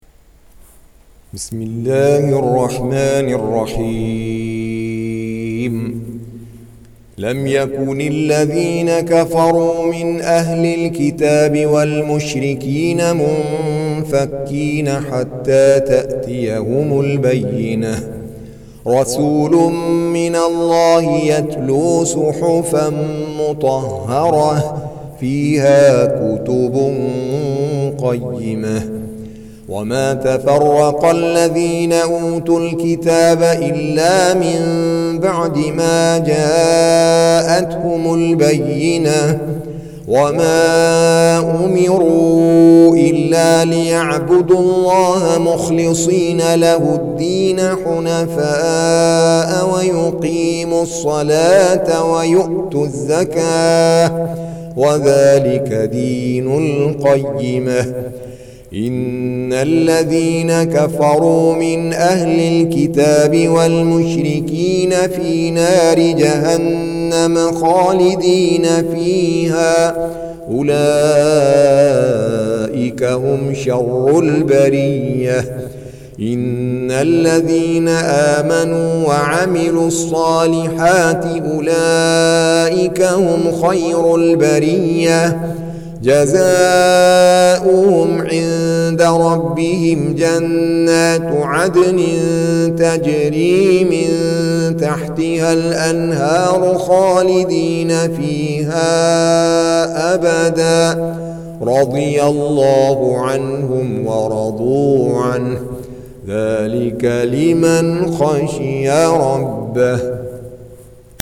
98. Surah Al-Baiyinah سورة البينة Audio Quran Tarteel Recitation
Surah Sequence تتابع السورة Download Surah حمّل السورة Reciting Murattalah Audio for 98. Surah Al-Baiyinah سورة البينة N.B *Surah Includes Al-Basmalah Reciters Sequents تتابع التلاوات Reciters Repeats تكرار التلاوات